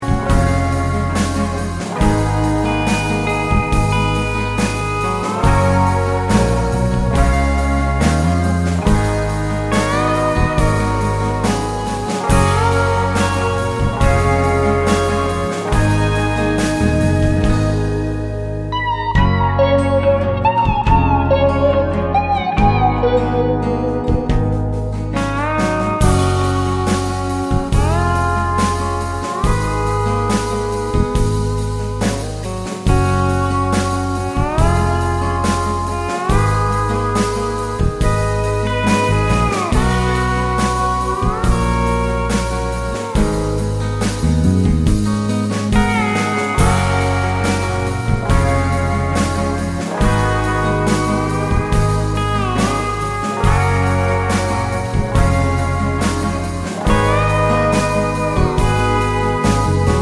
no Backing Vocals Country (Female) 3:32 Buy £1.50